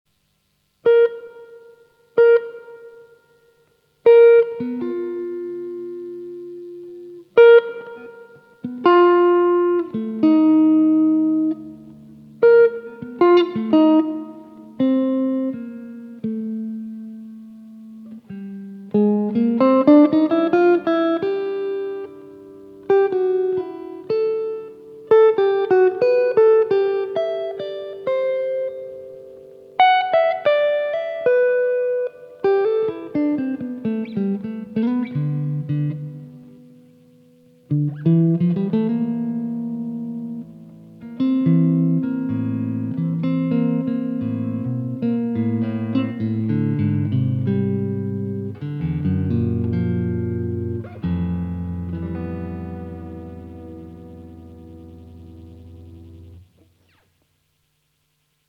Auf der Gitarre entdeckte ich erst sehr spät die Lust und Fähigkeit, selbst auch
annähernde Töne zu erzeugen.